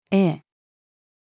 ええ-female.mp3